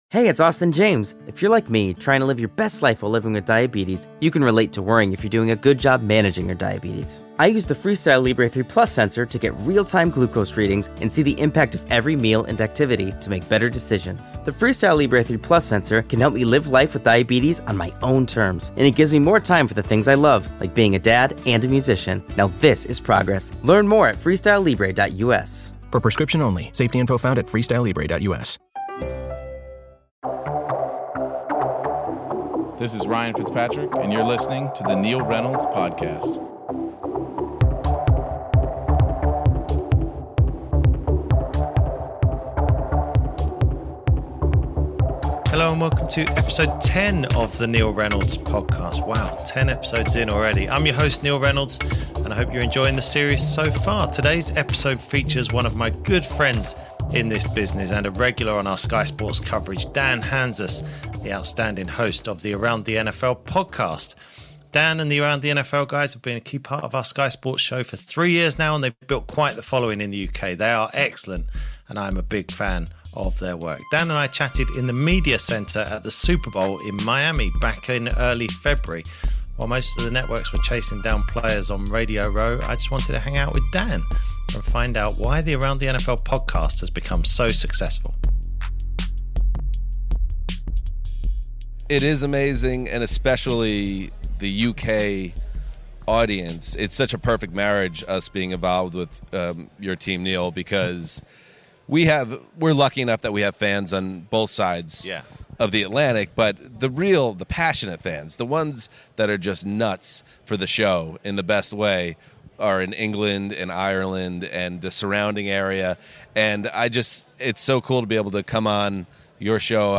Speaking in his best "God's voice"